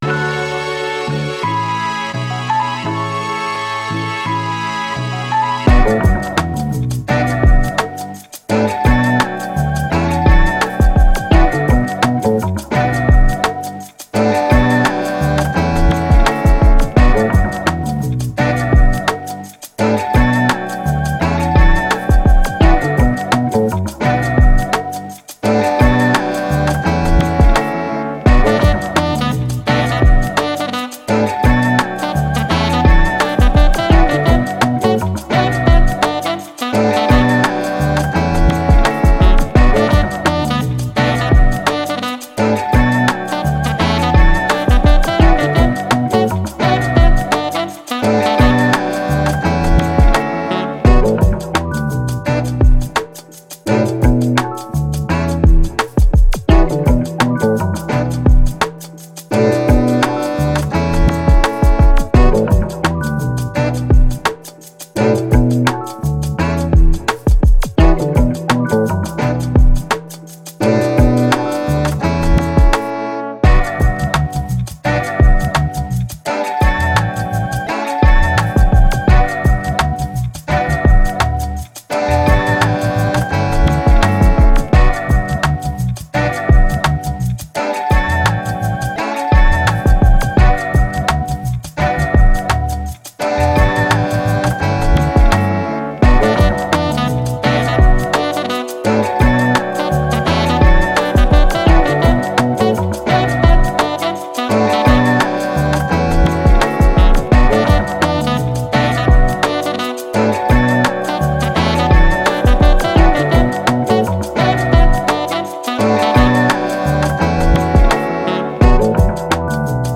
Hip Hop, Jazzy, Upbeat